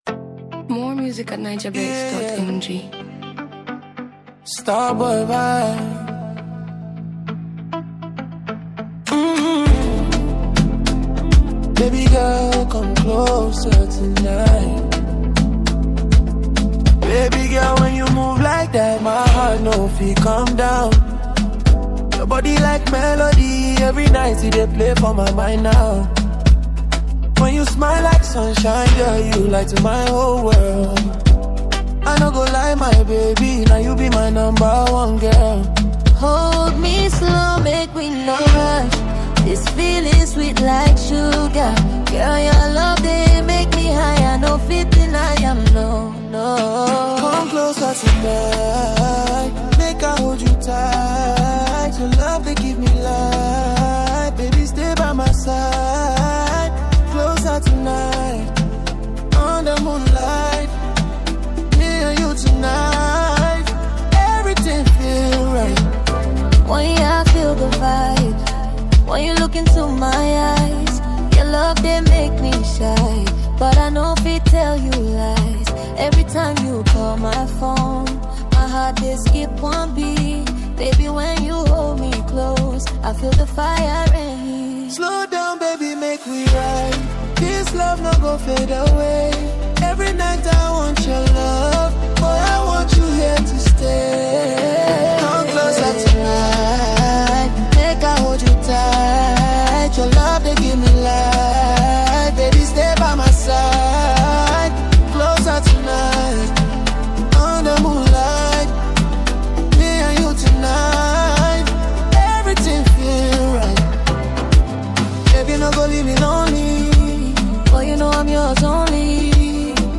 signature calm vocals